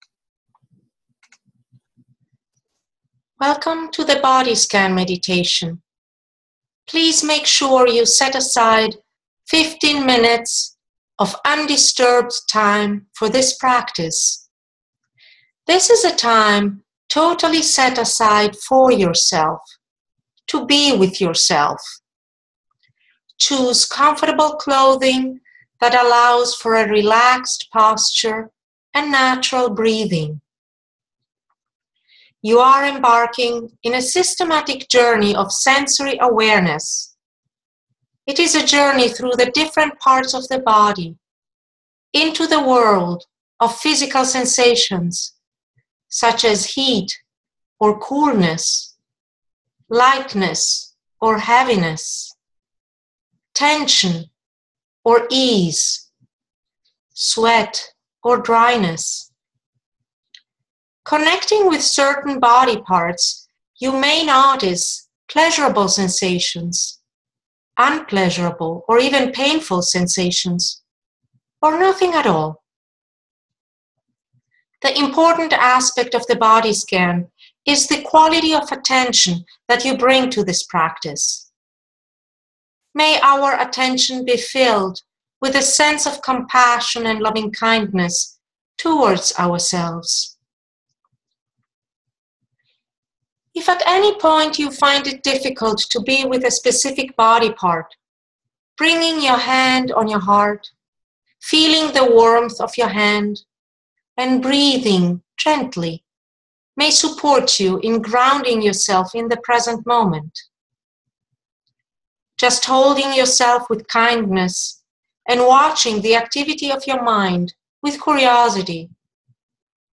Recorded Guided Meditations for Sitting Meditations and the Body Scan are also provided should you opt to use them while growing accustomed to these new practices.
Compassionate Body Scan Meditation 15 Min.m4a